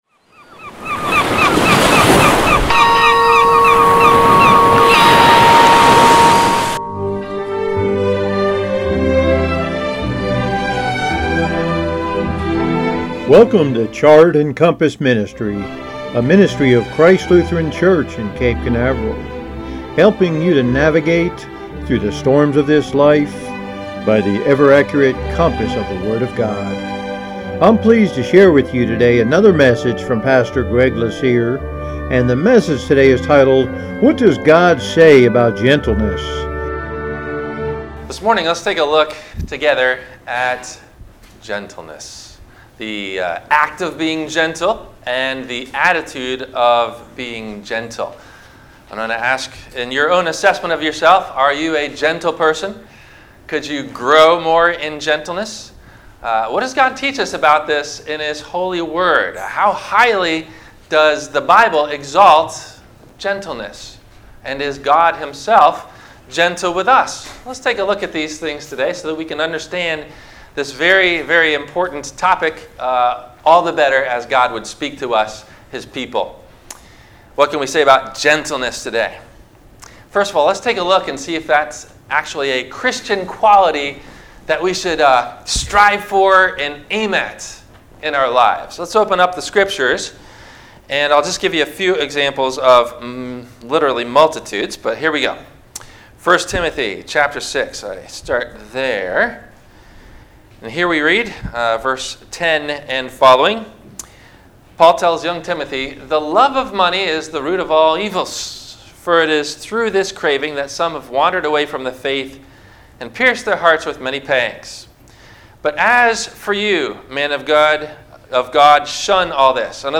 No Questions before Sermon.
What Does God Say About Gentleness? – WMIE Radio Sermon – October 07 2019